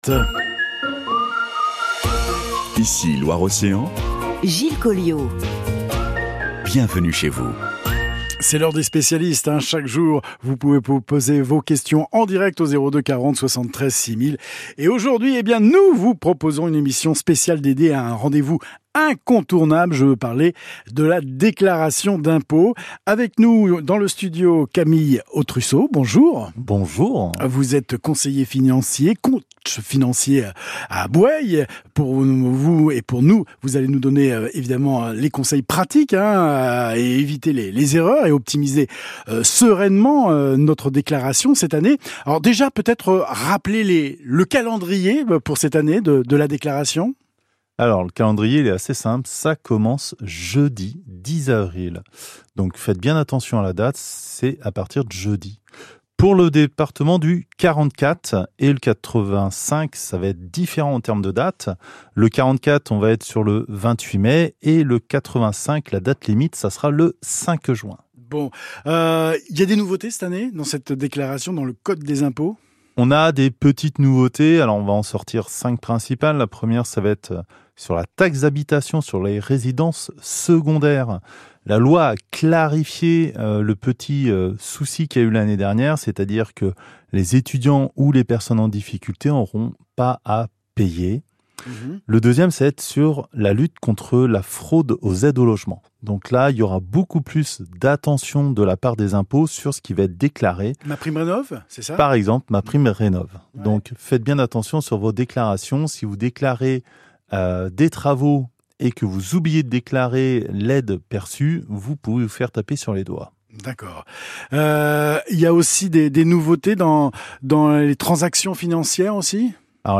👉 J’ai eu l’occasion de détailler ces nouveautés à la radio, lors de mon passage dans l’émission Les Spécialistes sur Ici Loire Océan, le 8 avril dernier.